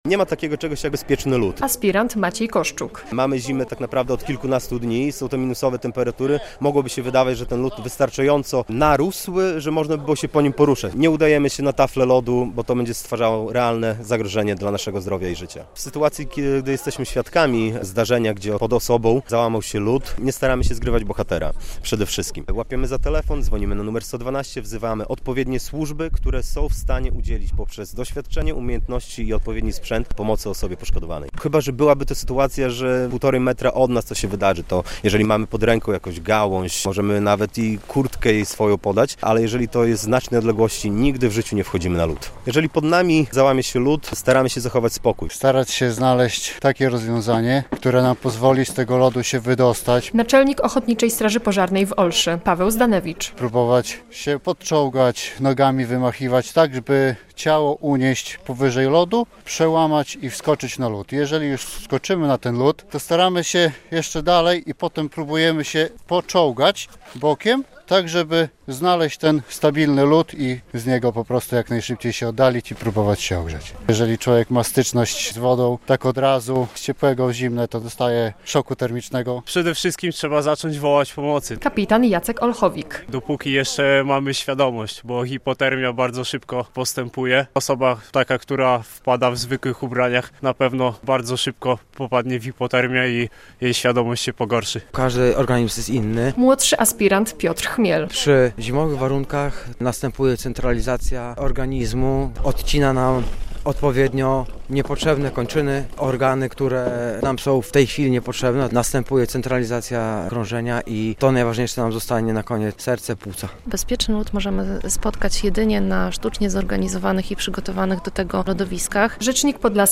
W środę (19.02) przeprowadzili ćwiczenia na zamarzniętym zalewie w białostockich Dojlidach, aby szkolić się w ratowaniu osób, które wpadły pod lód.